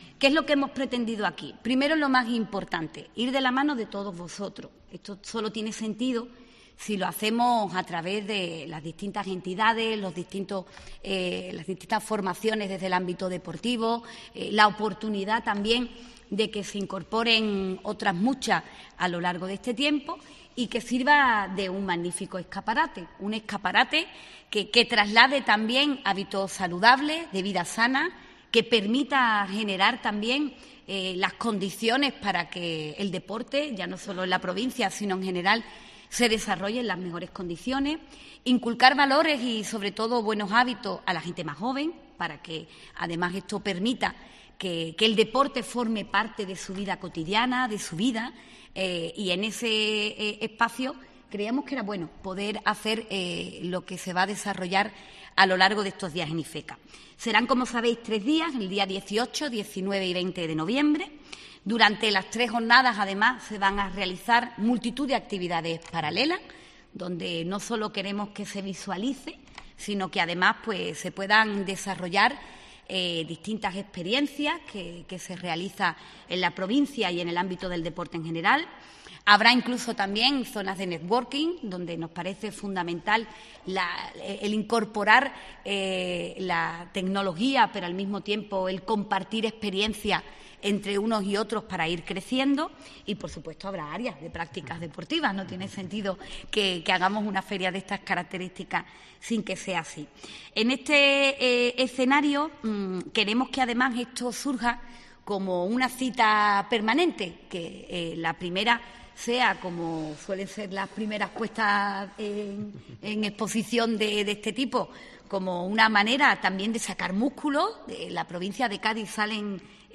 Irene García, la presidenta de la Diputación de Cádiz, y el diputado Jaime Armario presentan la I Feria del Deporte y la Vida Sana, que tendrá lugar del 18 al 20 de noviembre